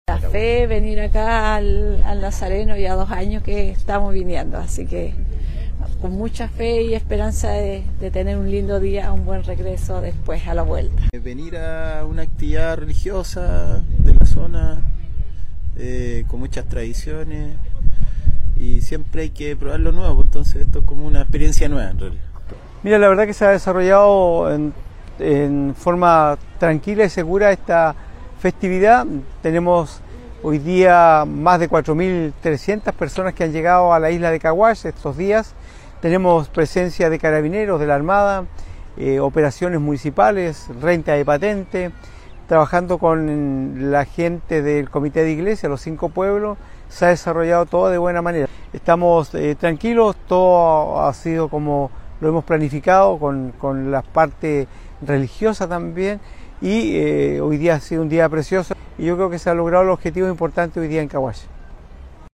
Durante esta peregrinación, los asistentes entregaron algunas consideraciones por su presencia en esta festividad.
También el alcalde de la comuna de Quinchao, René Garcés, hizo un balance de la masiva asistencia de fieles hasta la isla de la Devoción.